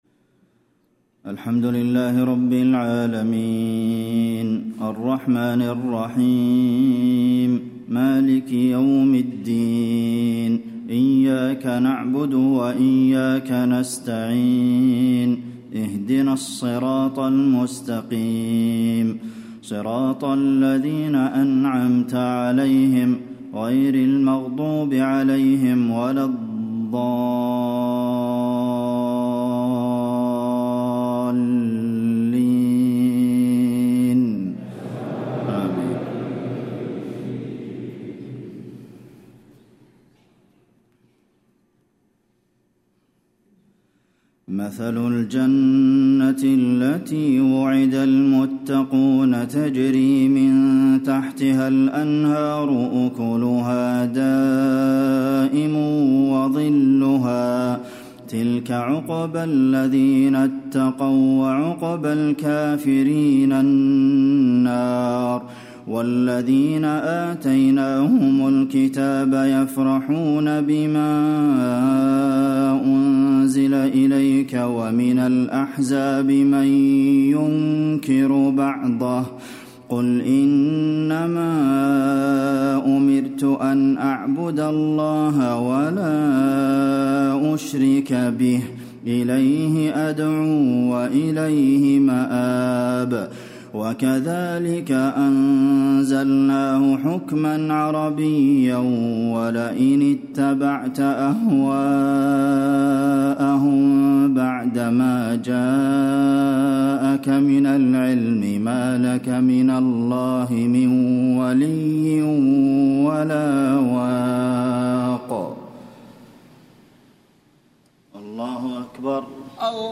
صلاة المغرب، الثلاثاء 1-8-1436 هـ من سورة الرعد > 1436 🕌 > الفروض - تلاوات الحرمين